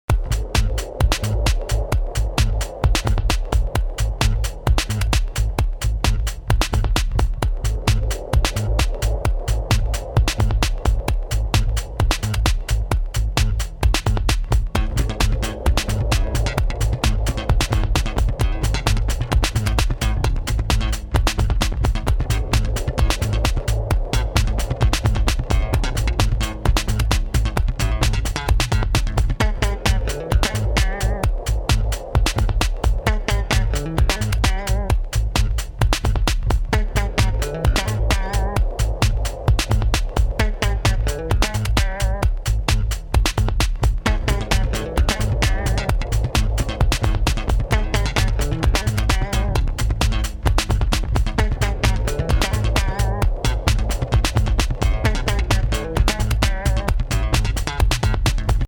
home of the daily improvised booty and machines -
bass grooves